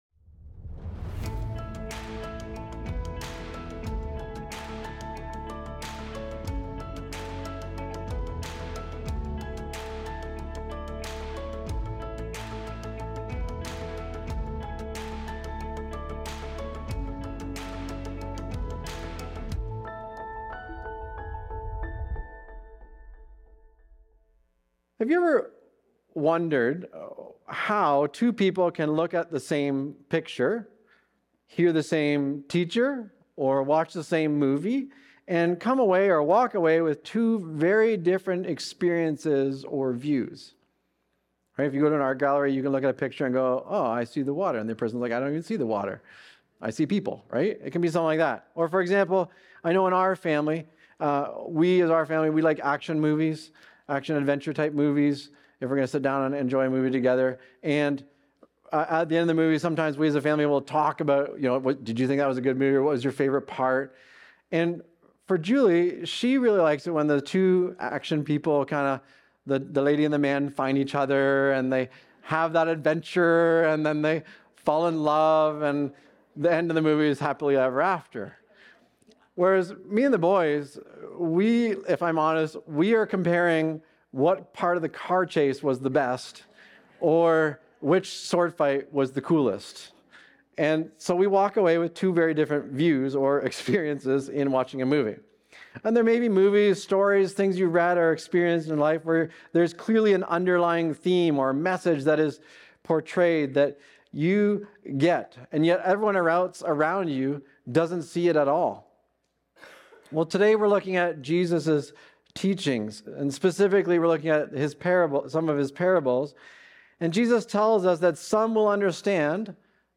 Recorded Sunday, March 8, 2026, at Trentside Fenelon Falls.